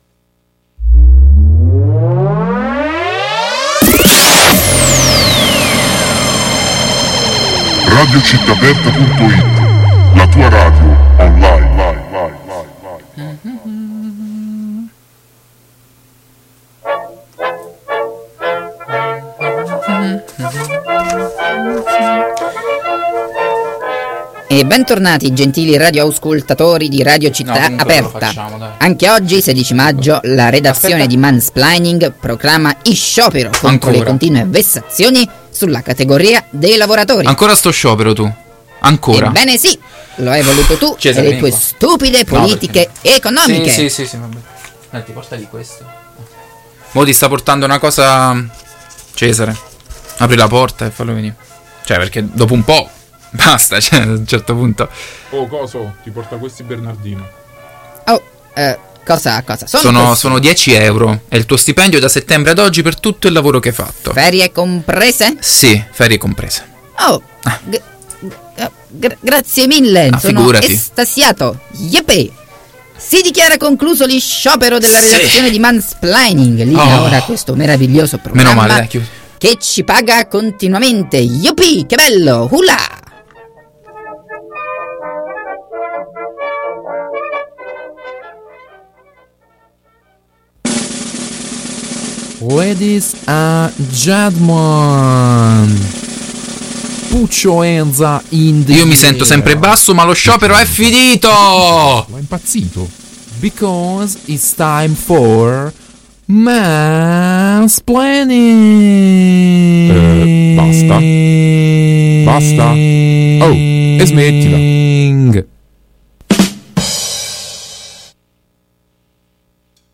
La redazione è scesa in campo per raccogliere le testimonianze di persone veramente adirate.
Durante la trasmissione è intervenuto un altro abitante del parco che ha provato a far valere le sue ragioni. Anche se con un tono poco apprezzabile.